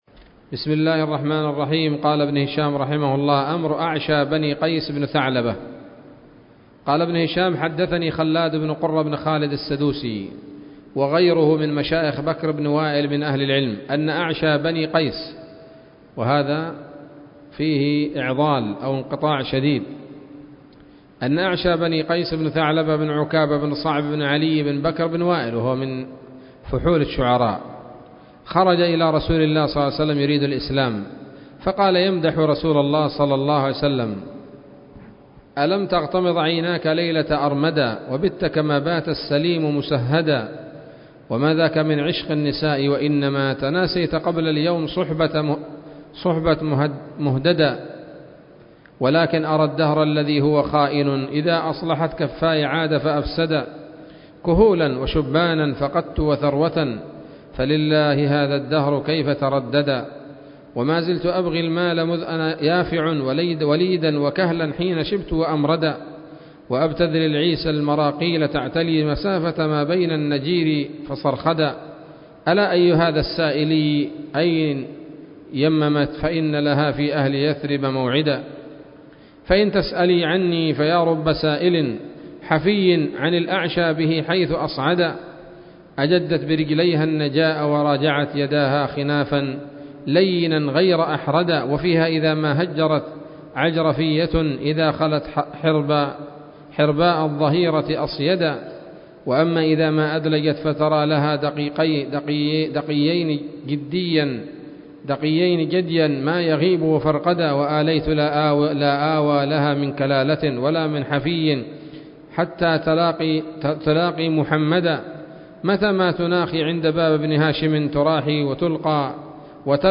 الدرس الخامس والأربعون من التعليق على كتاب السيرة النبوية لابن هشام